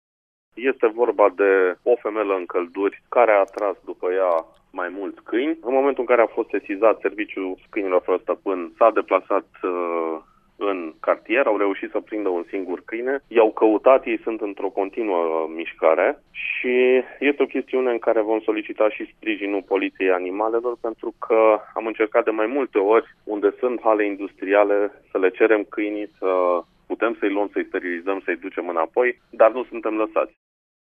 Viceprimarul Brașovului, Sebastian Rusu: